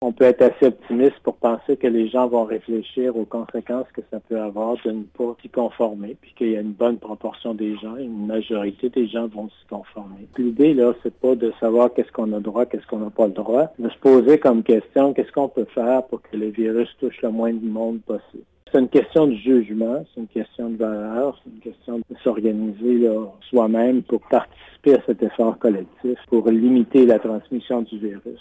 Le docteur, Yv Bonnier Viger, explique que deux scénarios sont envisageables pour une personne qui provient d’une zone orange ou rouge qui voudrait passer les fêtes aux Iles.